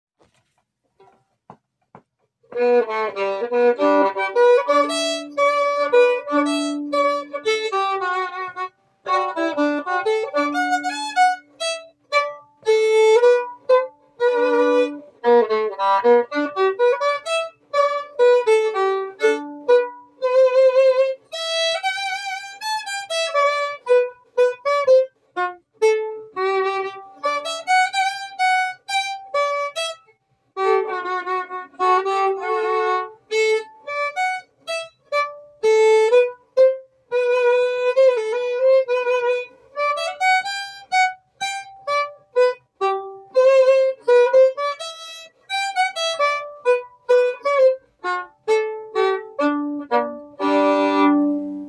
Key: G
Form: Waltz
M:3/4
Genre/Style: Maritime Canadian waltz